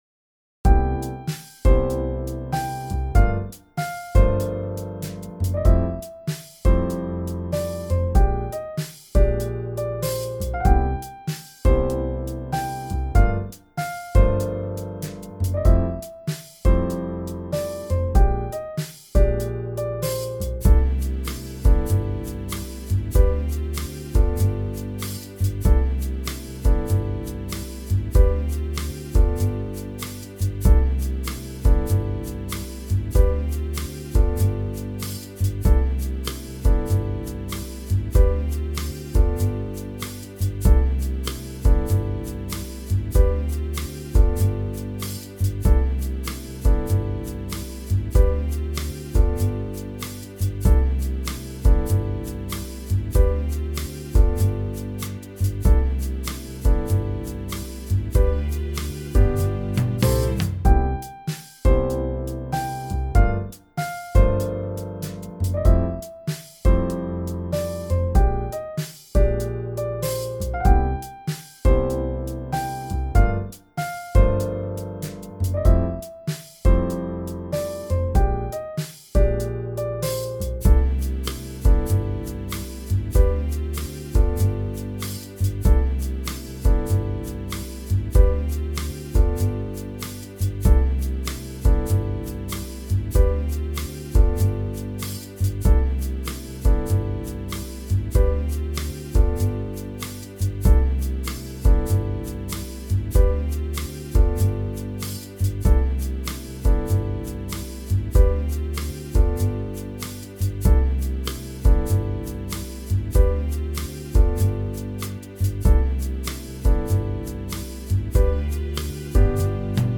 Backing
Fast